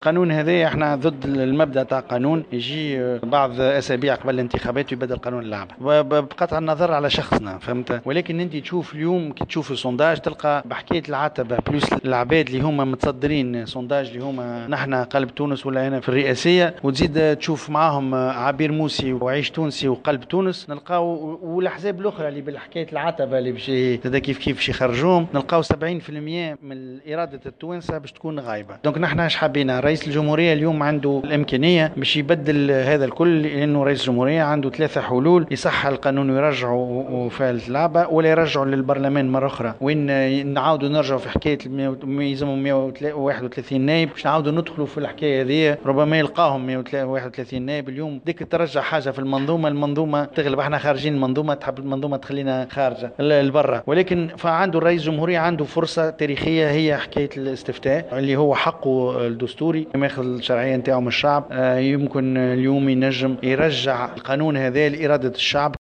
أكد نبيل القروي رئيس حزب قلب تونس في تصريح لمراسلة الجوهرة "اف ام" اليوم الخميس 11 جويلية 2019 أنه ضد تعديل القانون الانتخابي أسابيع قليلة قبل بدء الانتخابات التشريعية والرئاسية .